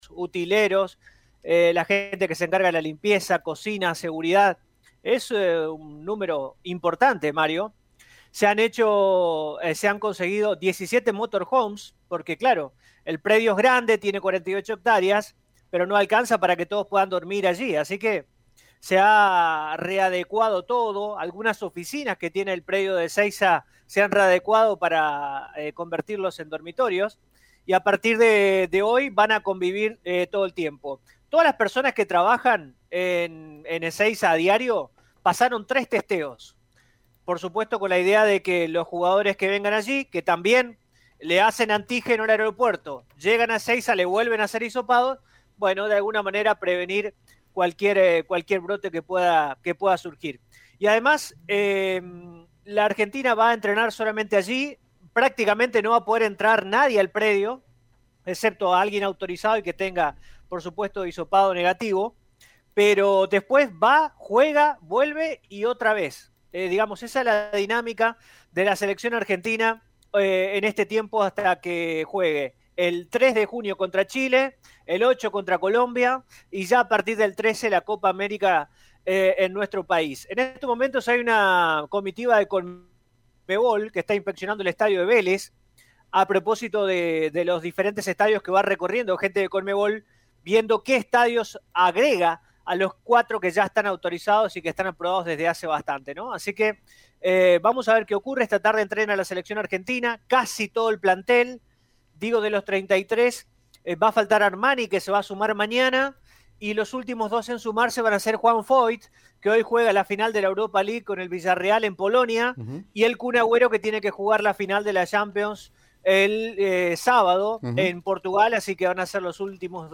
A través del móvil de Radio EME, en el centro de la capital provincial se observa que algunos negocios de la peatonal San Martín adoptaron la modalidad de atención «Take Away».